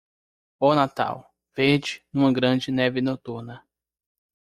Pronounced as (IPA) /naˈtaw/